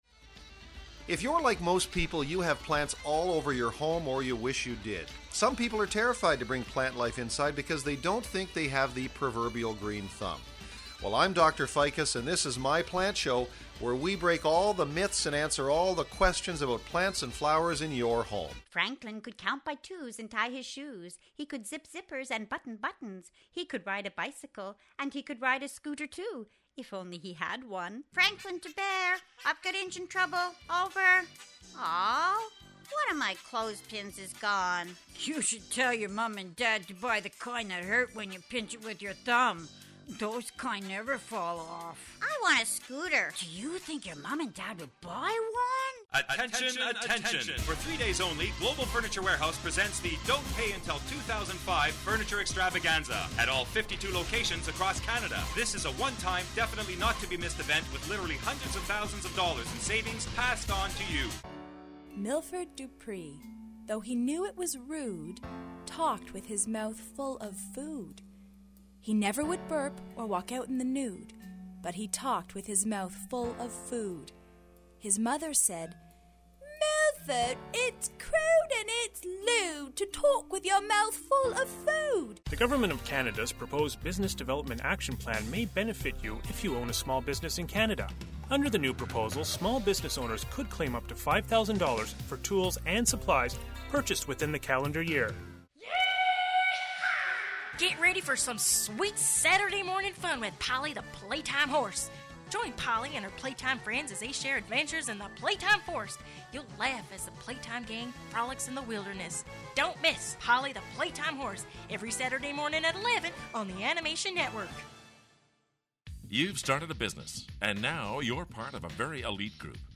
BELOW IS A SAMPLER FROM A FEW OF OUR CLIENTS AND THEIR ACTUAL VOICE DEMOS. CLIENTS RECORDED IN OUR STUDIO AND TRACKS WERE THEN EDITIED WITH MUSIC AND EFFECTS TO SOUND LIKE THE FINISHED PRODUCT.
NMB VOICE DEMO SAMPLER
2018 NMB Voice Demo Sampler.mp3